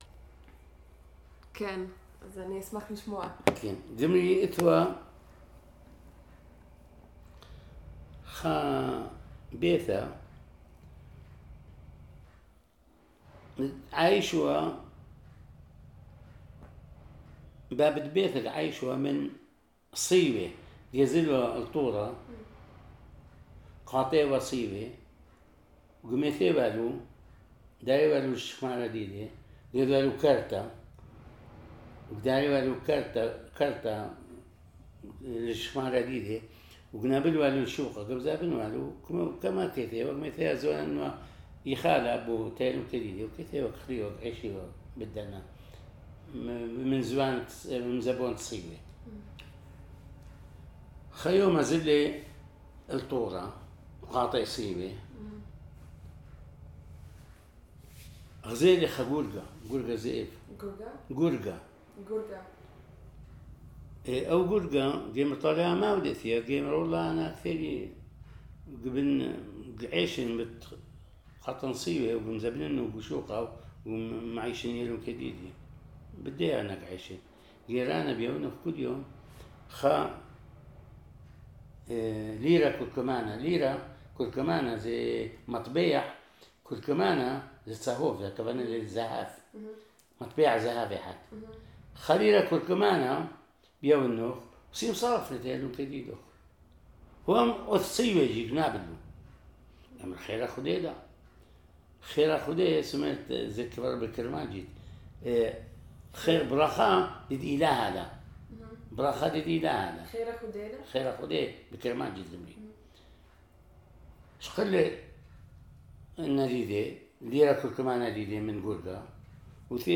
Dohok, Jewish: A man is a wolf to a wolf (folktale)